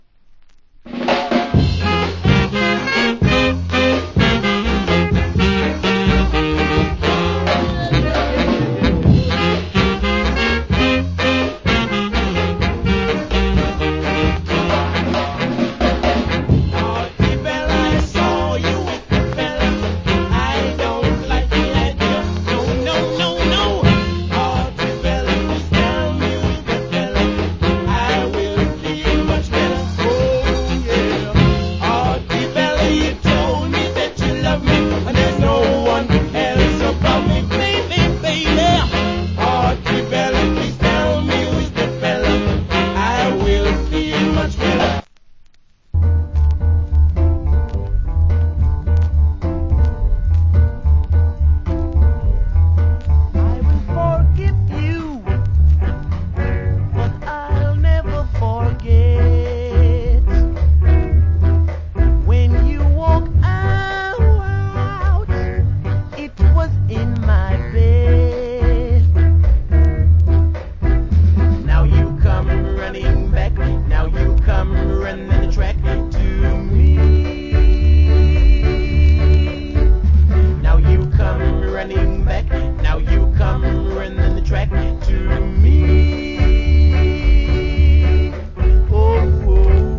Killer Duet Ska Vocal.